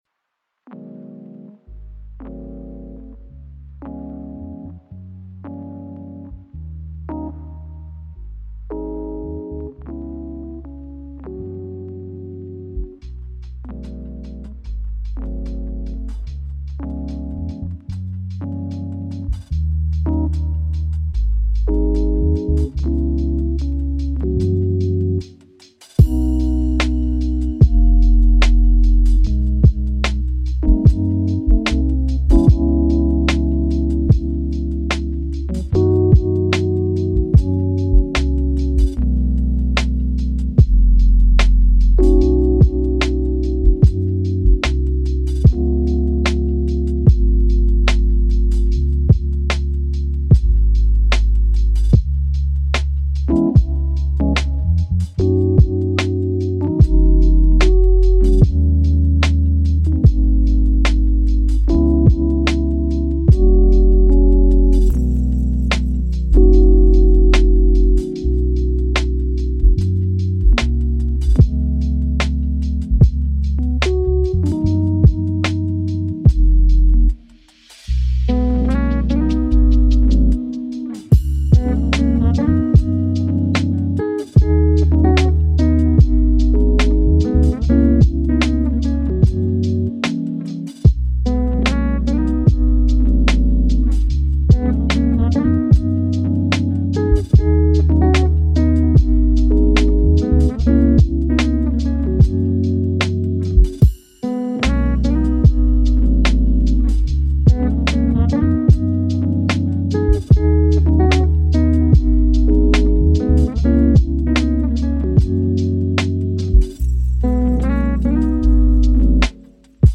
Océan 2h : Timer de Focus